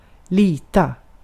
Ääntäminen
US : IPA : [ɹɪˈlaɪ]